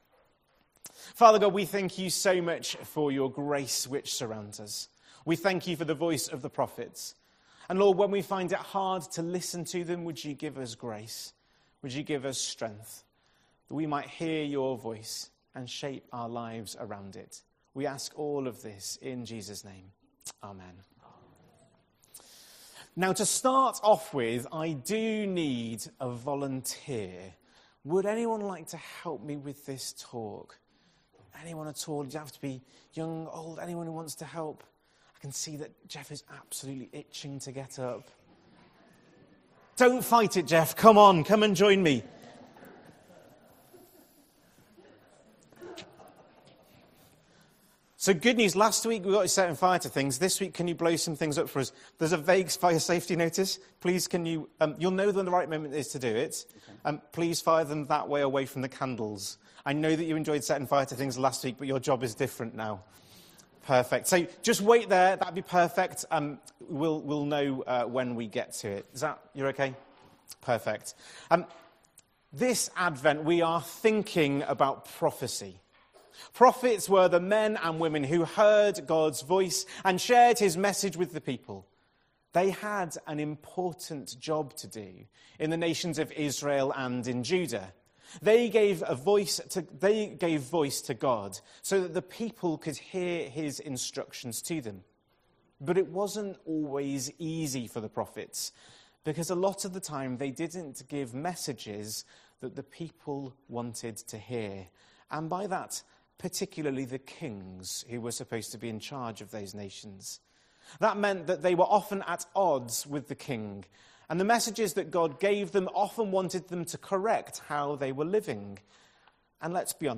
7th December 2025 Sunday Talk - St Luke's